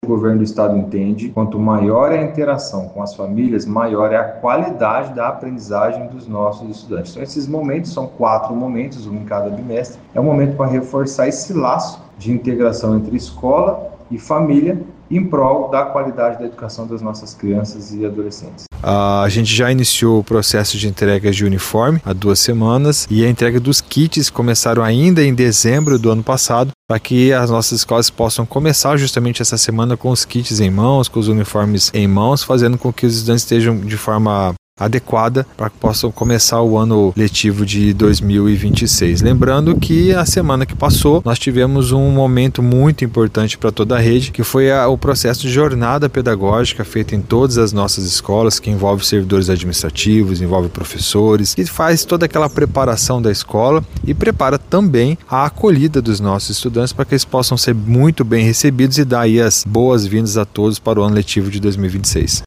Em entrevista à FM Educativa MS,  o secretário de Educação, Hélio Daher, cada escola terá autonomia para adequar o calendário e o currículo às suas necessidades específicas, desde que respeitadas as diretrizes estaduais.